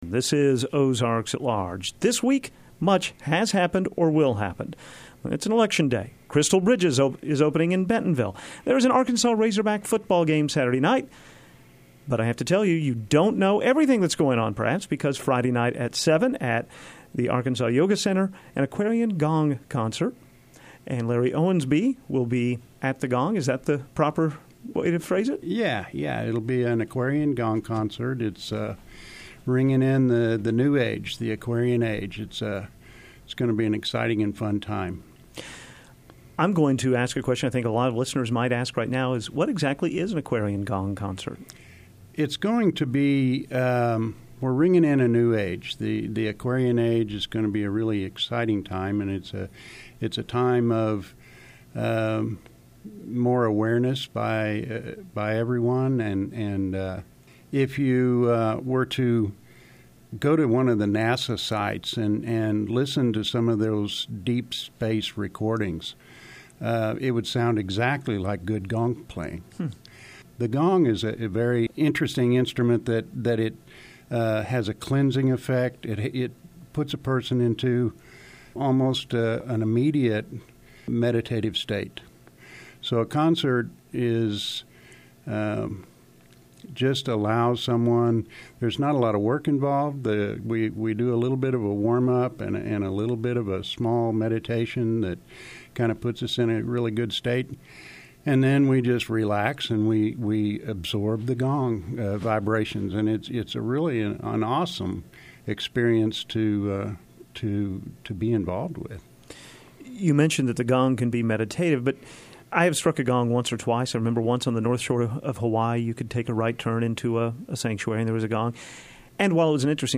The Sound of a Gong = Meditation!
Gong_Concert.mp3